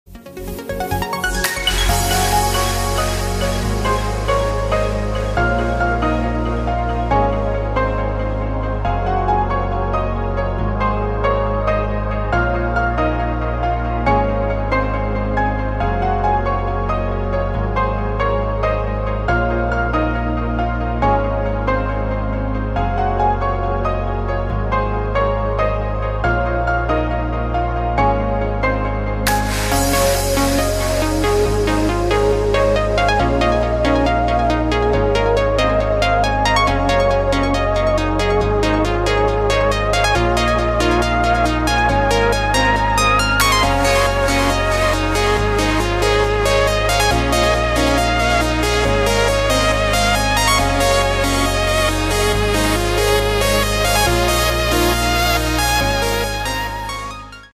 • Качество: 128, Stereo
Electronic
электронная музыка
спокойные
без слов
клавишные
Trance
melodic trance